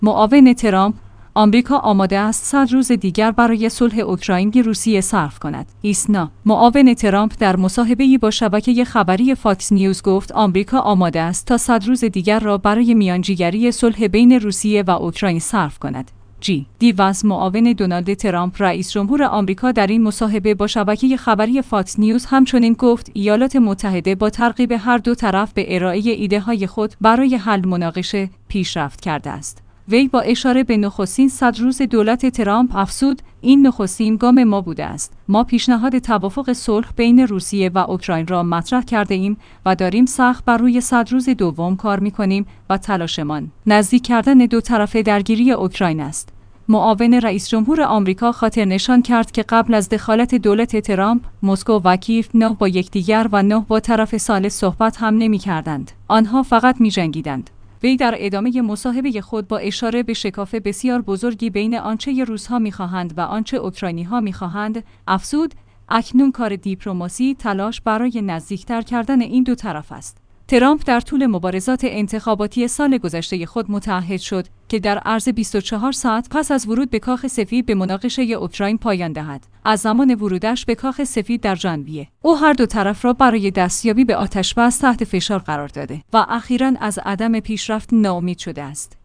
ایسنا/ معاون ترامپ در مصاحبه‌ای با شبکه خبری فاکس نیوز گفت آمریکا آماده است تا ۱۰۰ روز دیگر را برای میانجی‌گری صلح بین روسیه و اوکراین صرف کند.